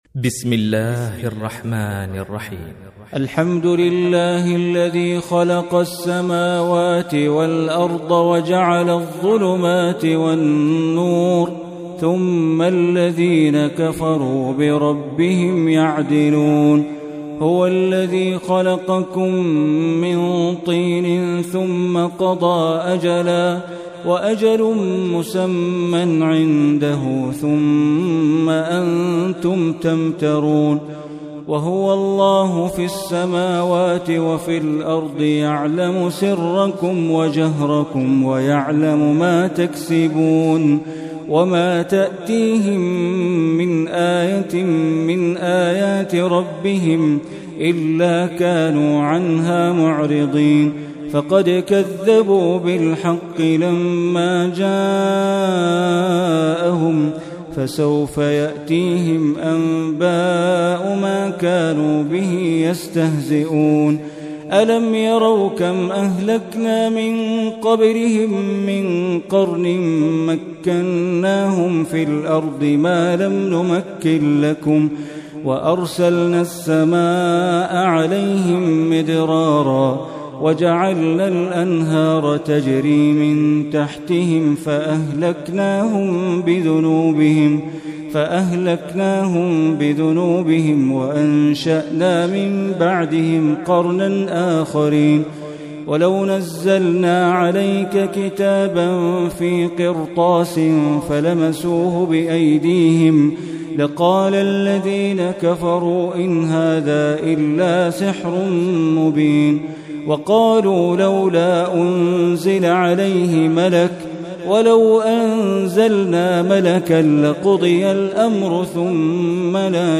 Surah Al-Anaam is 6th chapter of Holy Quran. Listen or play online mp3 tilawat / recitation in arabic in the beautiful voice of Sheikh Bandar Baleela.